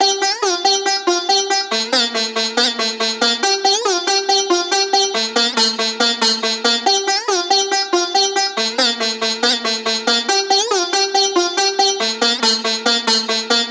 Gully-Loops-Hip-Hop-God-Drop-Loop-BPM-140-G-Min.wav